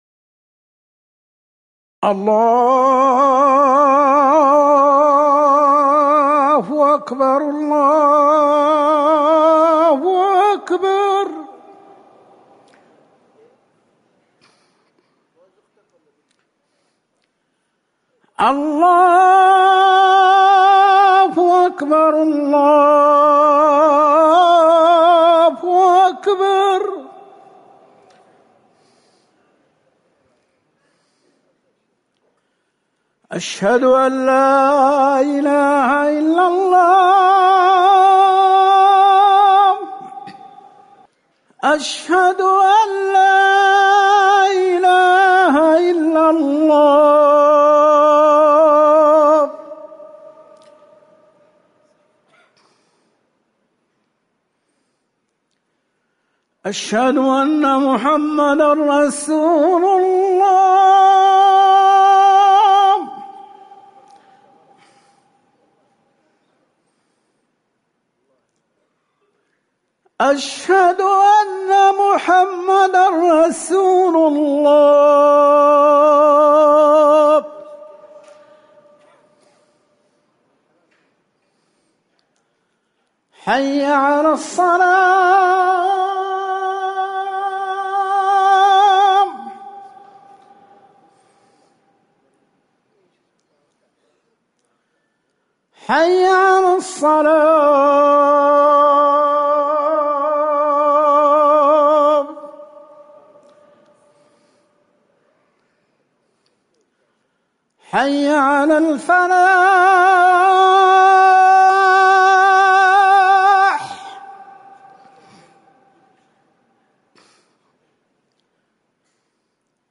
أذان الفجر الثاني
تاريخ النشر ٢٩ محرم ١٤٤١ هـ المكان: المسجد النبوي الشيخ